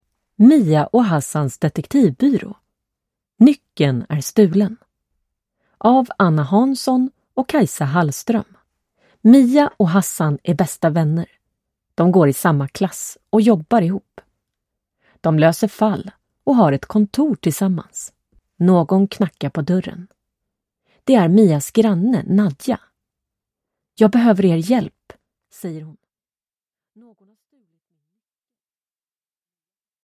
Nyckeln är stulen! (ljudbok) av Anna Hansson